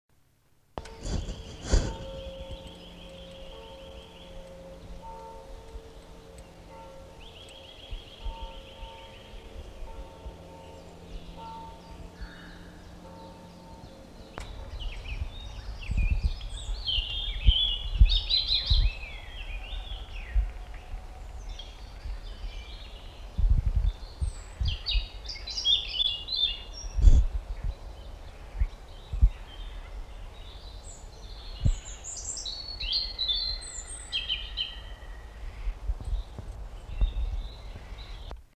Chants d'oiseaux et sonnerie de cloche
Aire culturelle : Savès
Lieu : Gers
Genre : paysage sonore